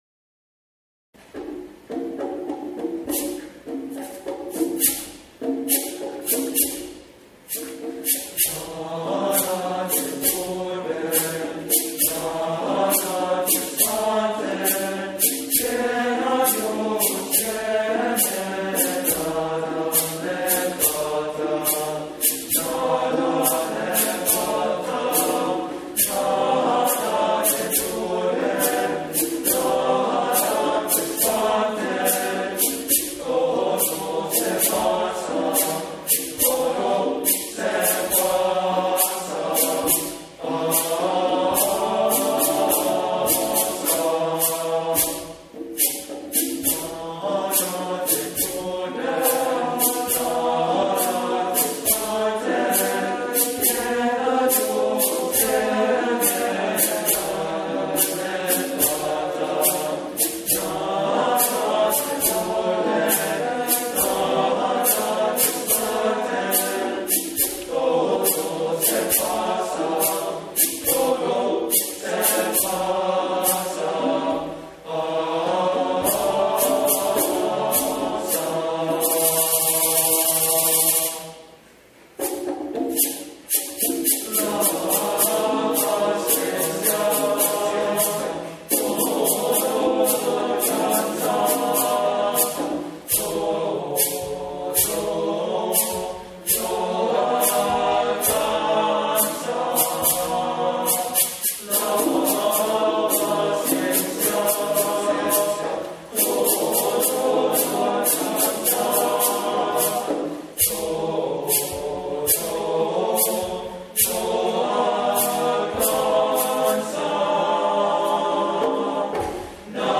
Voicing: TBB